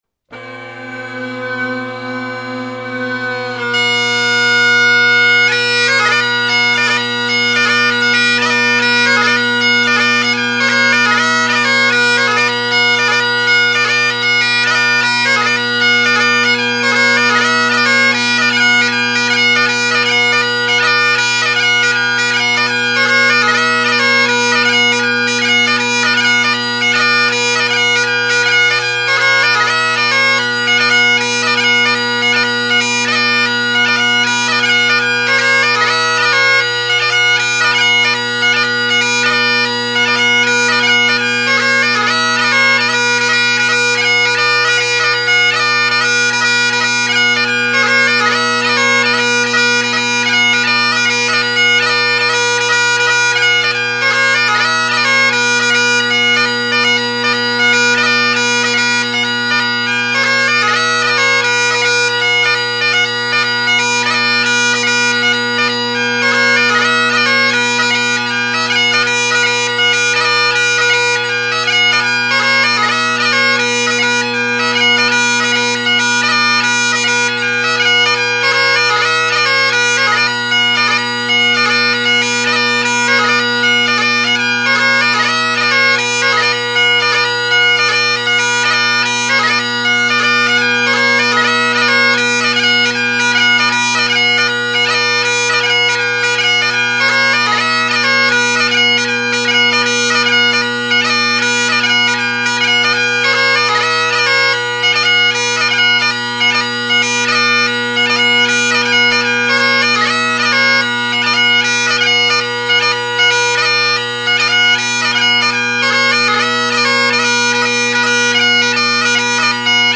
Below are several recordings made over several days of most of my collection of bagpipes.
Jacky Latin (mic off to the left) – not the audio from the video above (Pipes are Colin Kyo with Ackland Overtone drone reeds = why you get two sound perspectives.